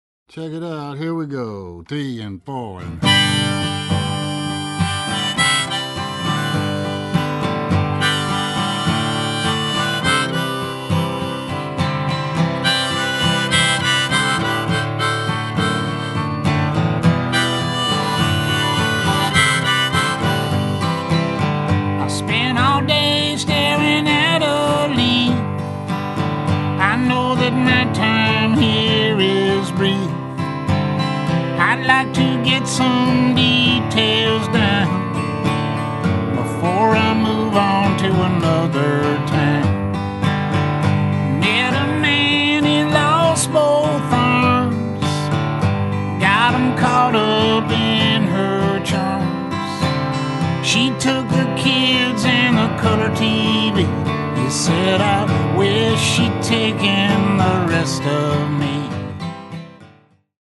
(original two-track demos)
introspective ballad